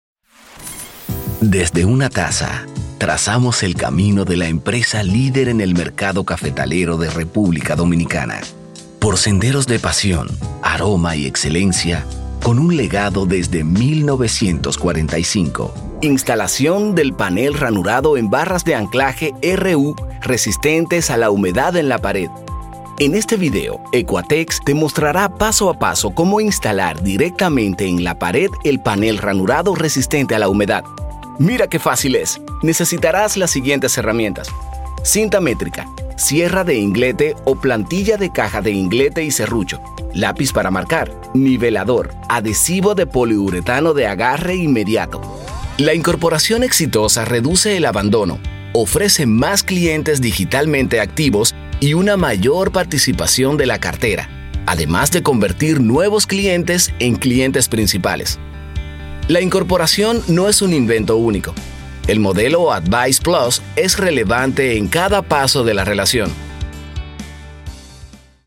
Spanish (Latin American)
Native Voice Samples
Corporate Videos